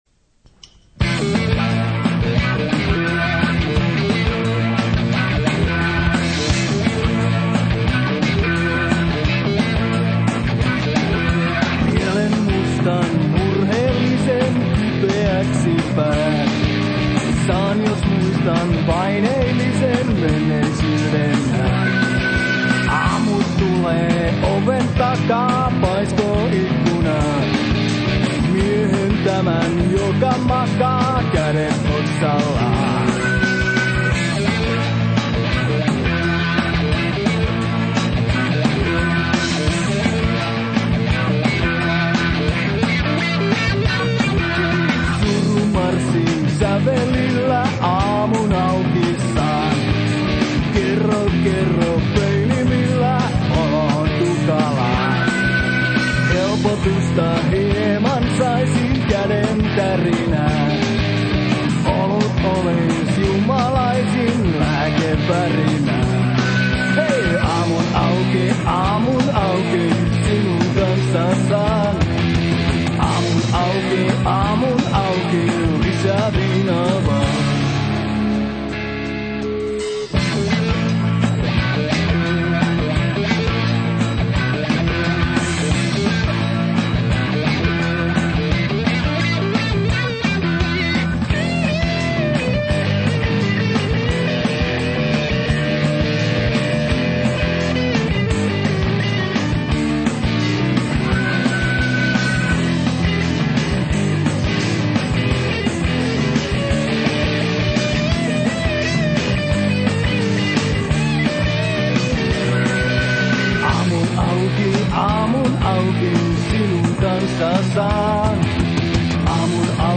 laulu, kitara
basso
rummut
taustalaulu
kosketinsoittimet
dobro
piano